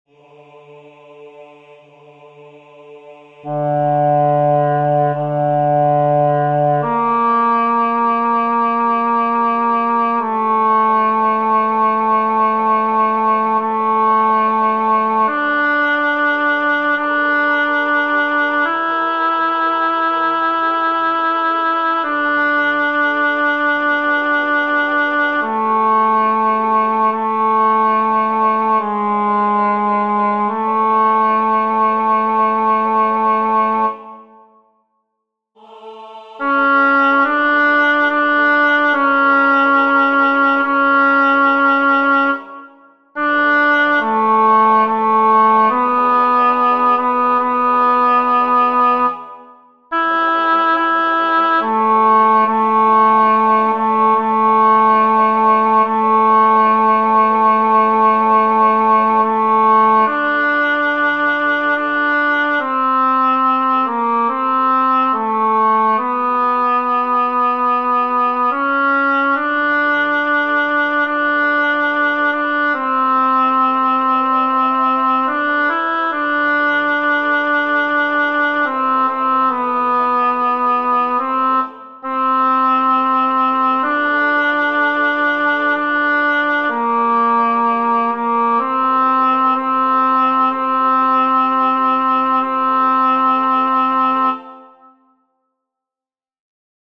Para el aprendizaje de las diferentes melodías os dejo unos MIDI instrumentales,
miserere-lotti-tenor.mp3